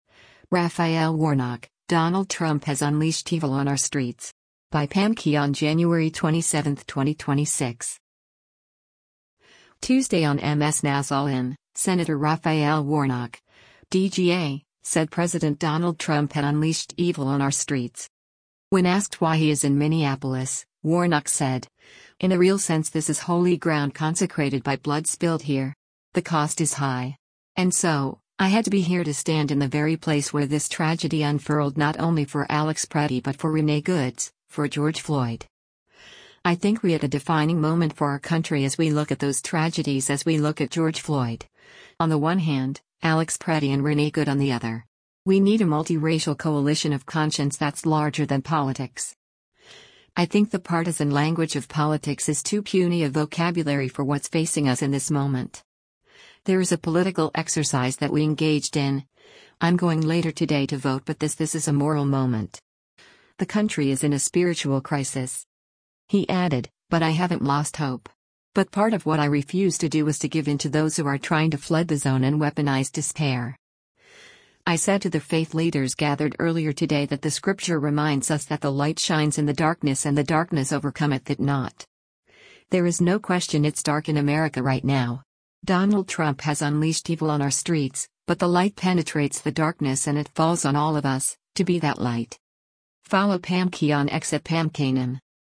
Tuesday on MS NOW’s “All In,” Sen. Raphael Warnock (D-GA) said President Donald Trump had “unleashed evil on our streets.”